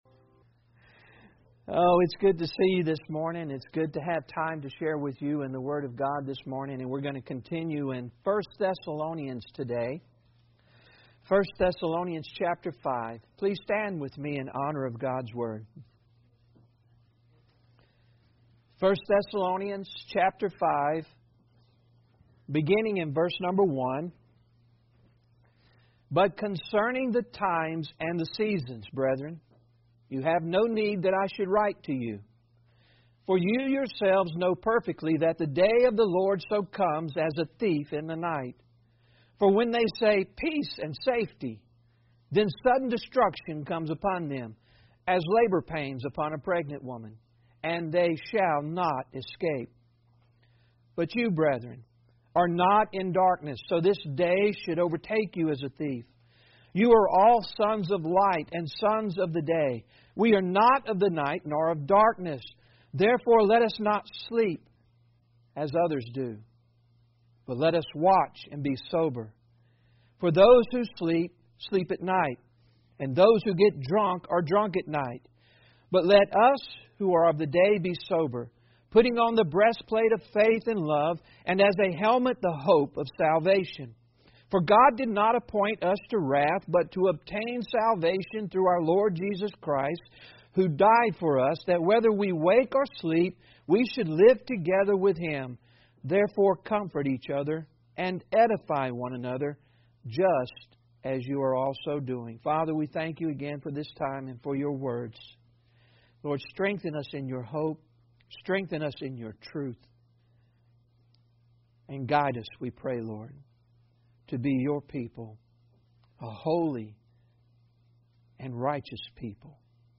1 Thessalonians 5:1-11 Service Type: Sunday Service Bible Text